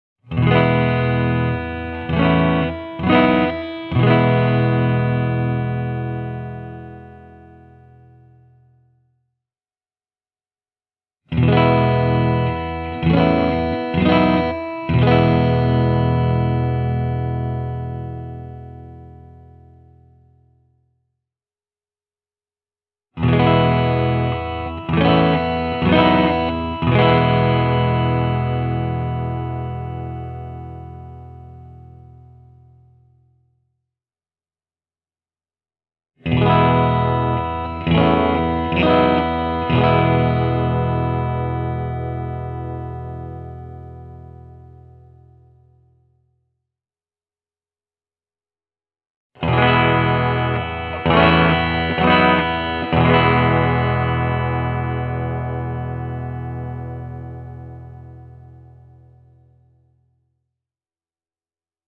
SSL-3-version signaalitaso ei ole silmiinpistävästi kuumempi kuin SSL-1-kitarassa. Mikkisetin Hot Strat -nimitys tulee pitkälti mikkien voimakkaalta keskialueelta. Tämä on paksumpi ja lihaksikkaampi näkemys Straton soundista: